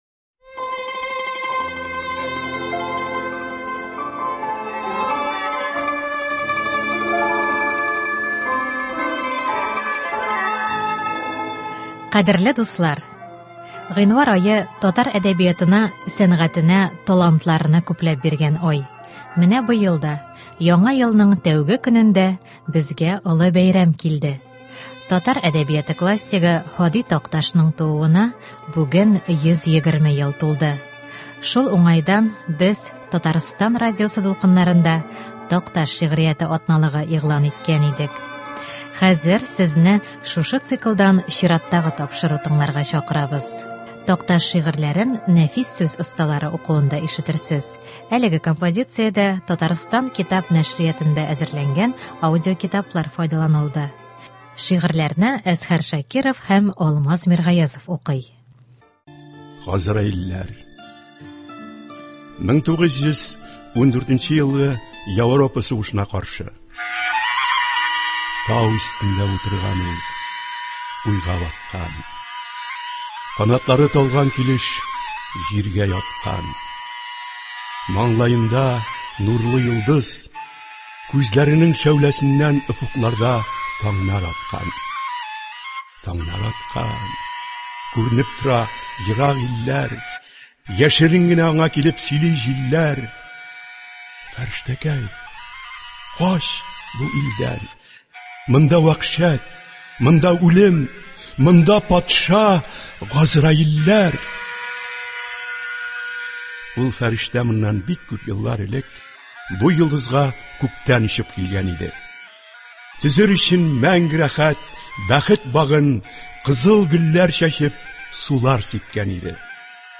Такташ шиыгрьләрен нәфис сүз осталары укуында ишетерсез. Әлеге композициядә Татарстан китап нәшриятында әзерләнгән аудиокитаплар файдаланылды.